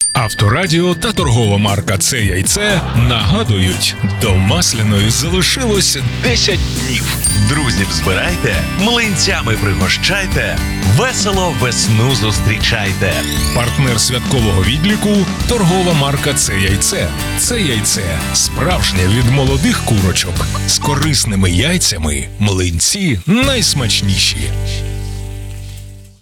Также для запуска рекламной кампании на радио было записано 5 разных радио роликов для 5-ти радиостанций с уникальной и эмоциональной подачей, задорным и привлекательным текстом (слушайте ниже!).
Реклама на Авторадио — прослушать